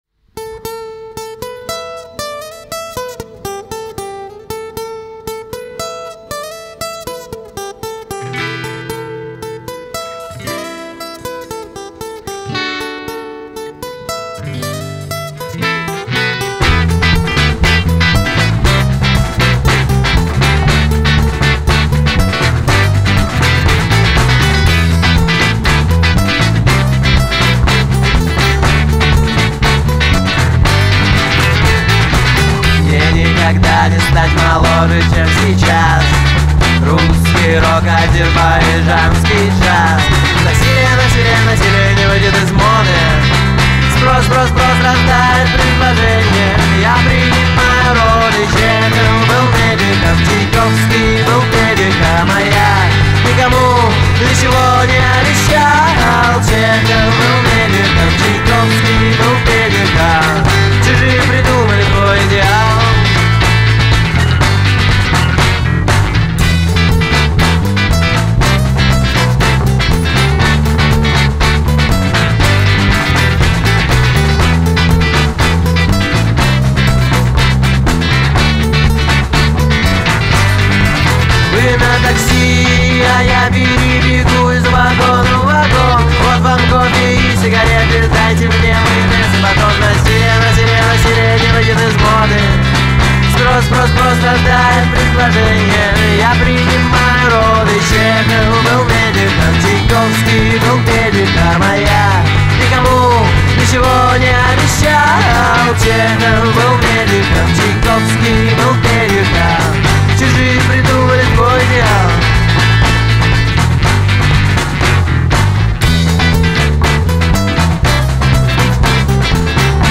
соединяющему панк и поэзию.